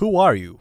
Voice Lines / Dismissive
who are you.wav